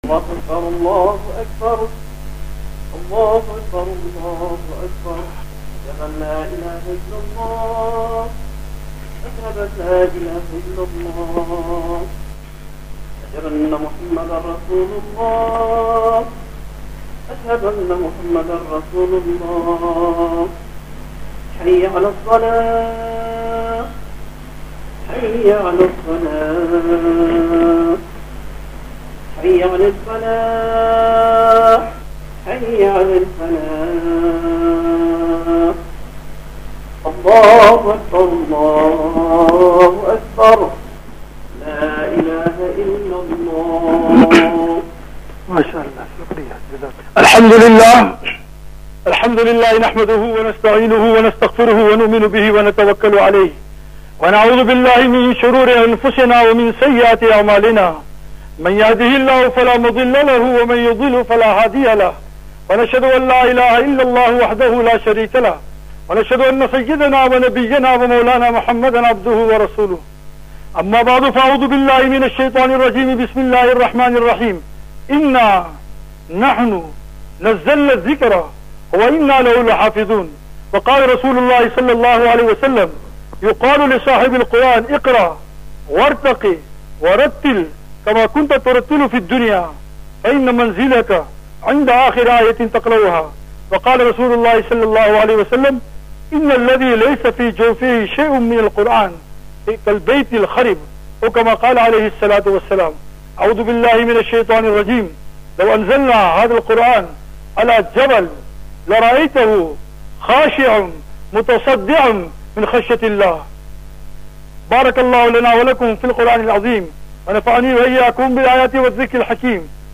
ENGLISH LECTURE, KHUTBA ,JUMU'A SALAAH AND DU'A
Palm Ridge Musjid, Gauteng. South Africa